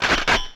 gun_cock.ogg